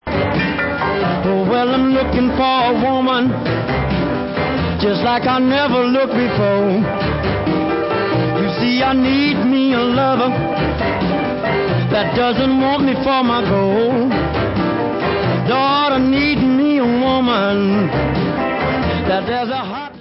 The best r&b guitarist in the world!? he did it all!!